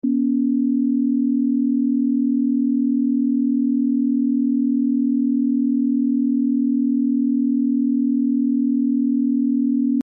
ADHD brain? 40 Hz helps sound effects free download